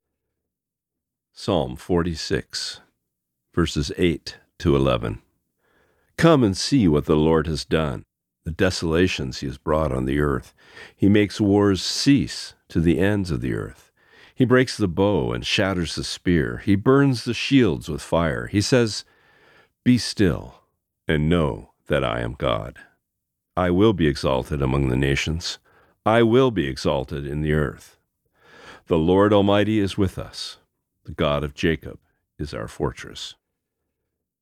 Today’s Reading: Psalm 46:8-11